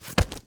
Body_fall.ogg